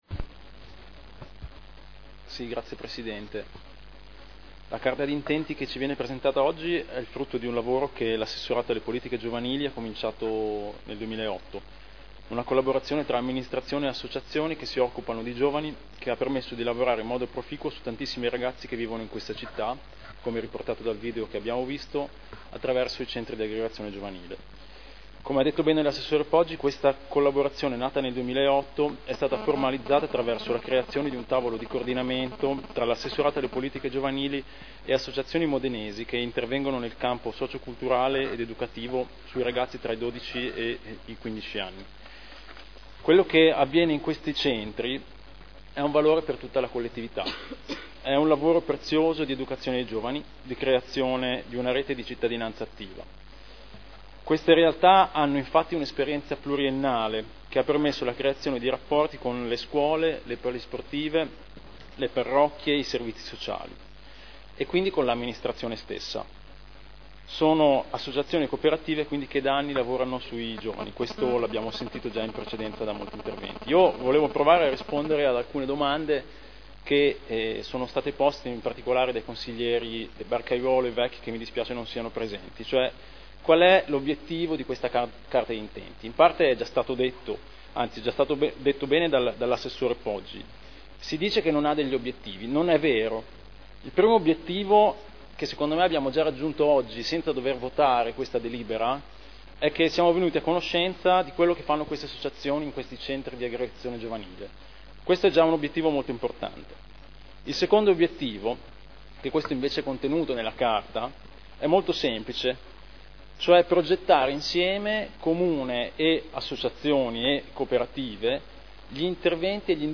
Stefano Rimini — Sito Audio Consiglio Comunale